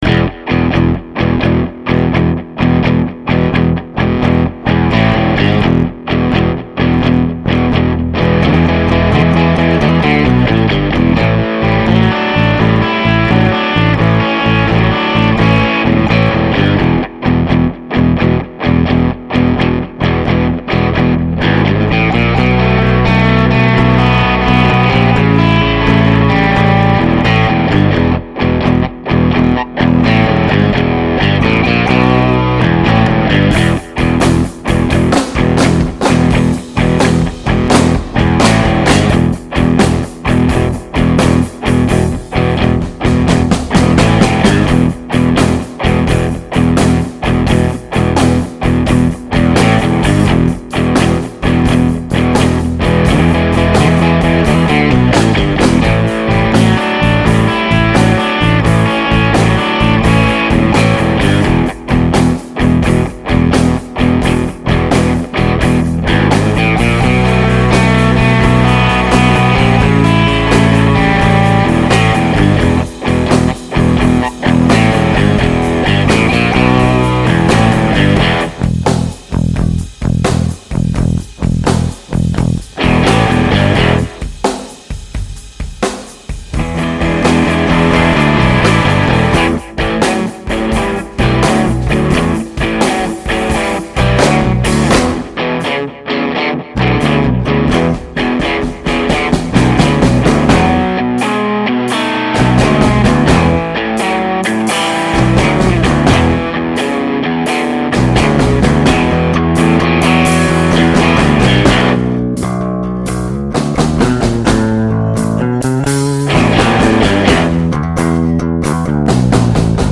Touch To My Heart (Rock)